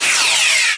laser-beam-01.ogg